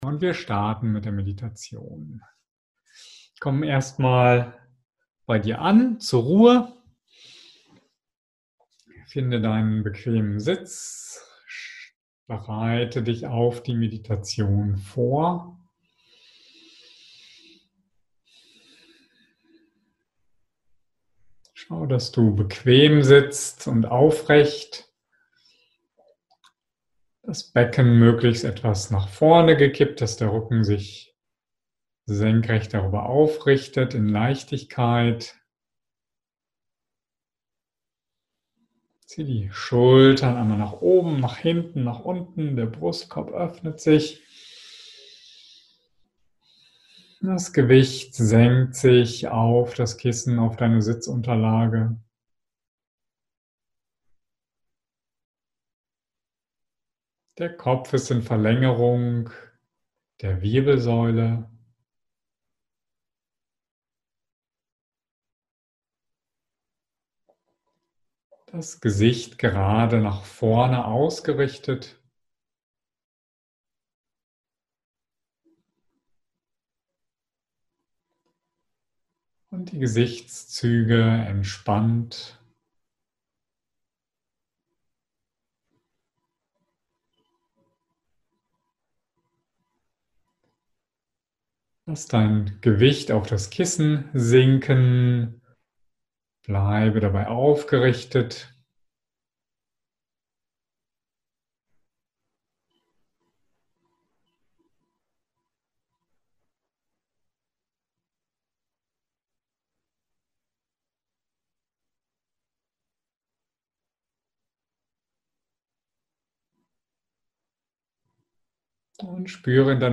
Fantasiereise Rosenmeditation